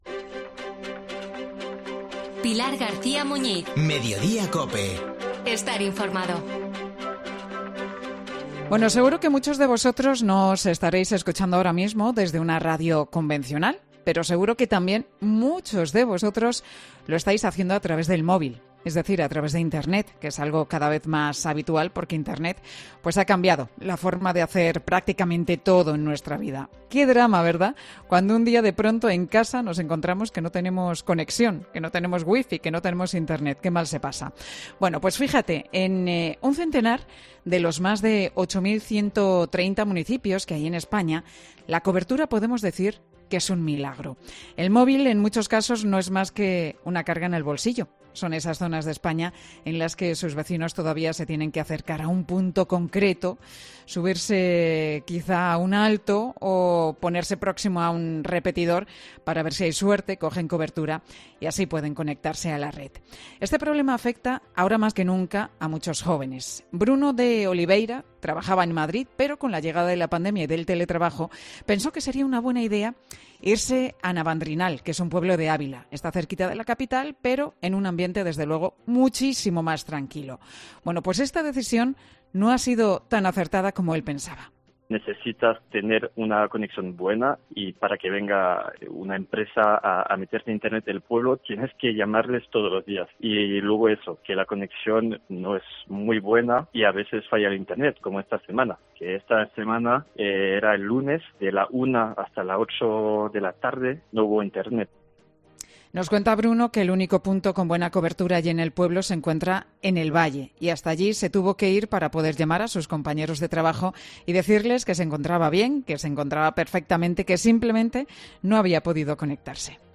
Contesta al teléfono desde la calle porque en casa no recibe ni una sola llamada, “aquí estoy, pasando frío”, dice.